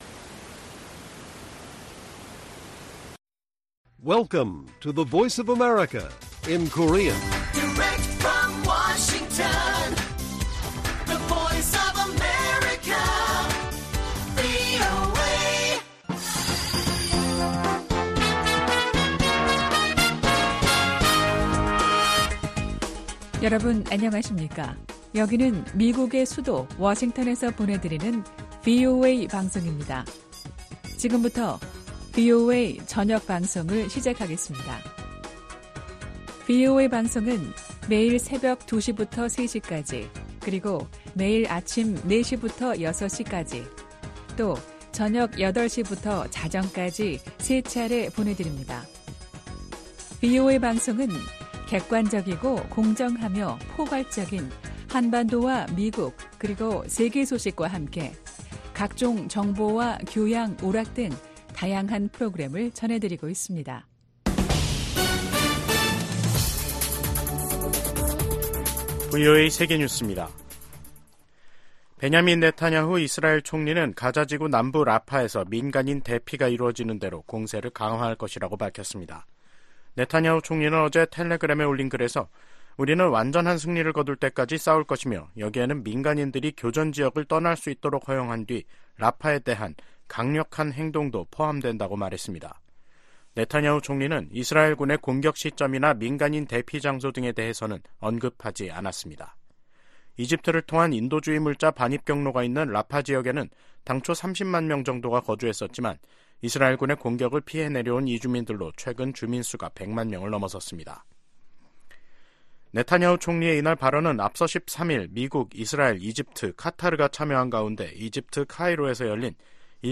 VOA 한국어 간판 뉴스 프로그램 '뉴스 투데이', 2024년 2월 15일 1부 방송입니다. 북한이 신형 지상 대 해상 미사일 시험발사를 실시했다고 관영 매체가 보도했습니다.